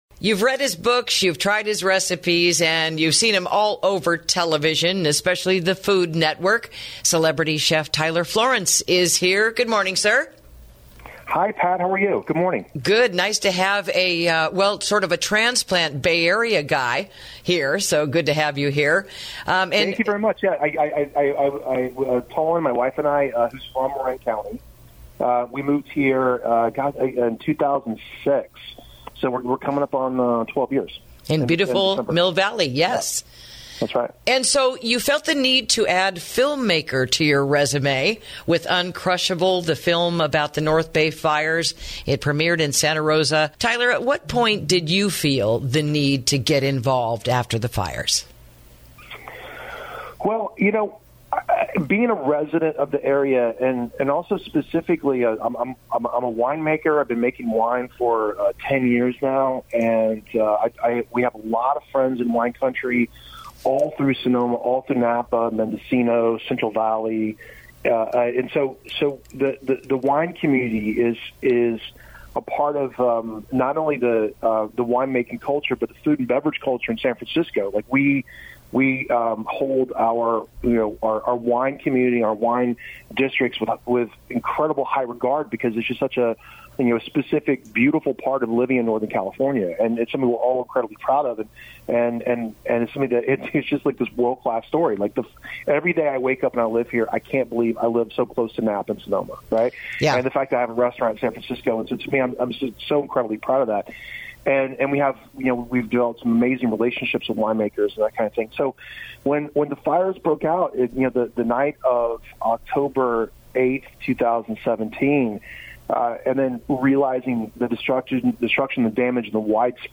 INTERVIEW: "Uncrushable" Documentary Screening at the Upcoming Napa Valley Film Festival | KSRO 103.5FM 96.9FM & 1350AM